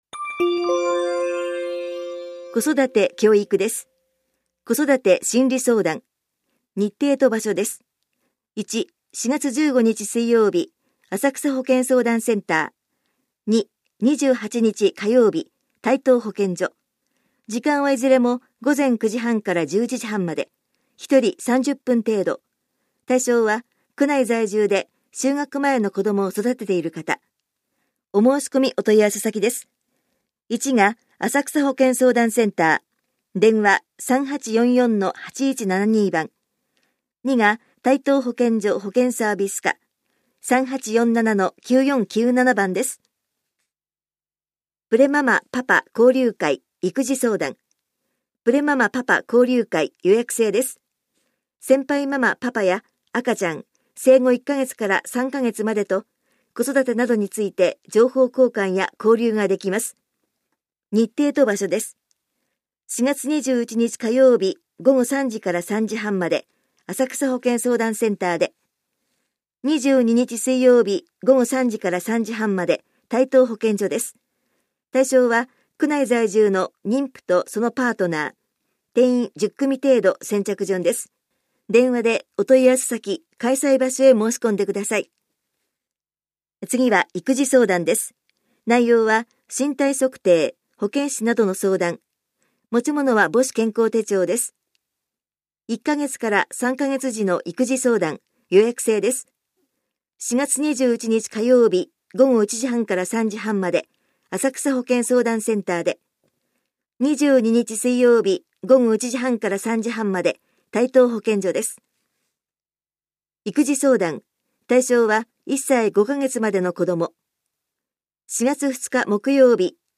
広報「たいとう」令和2年3月20日号の音声読み上げデータです。